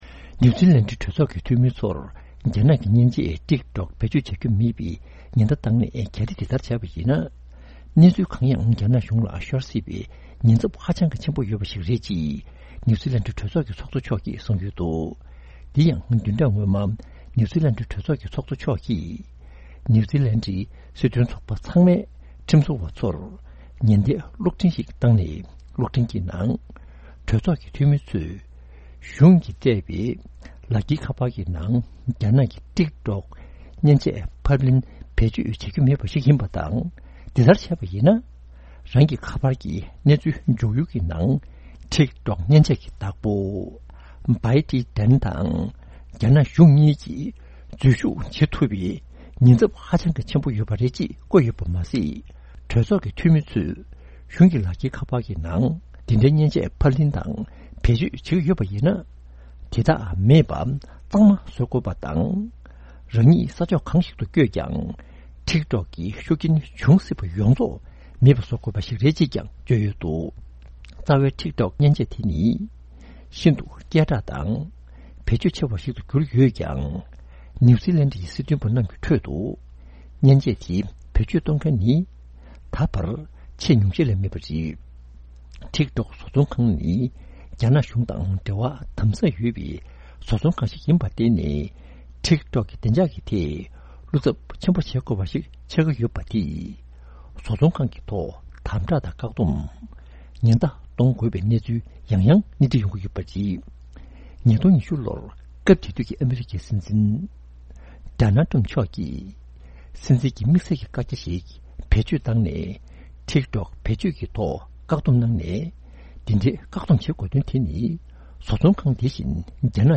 སྙན་སྒྲོན་ཞུ་ཡི་རེད།།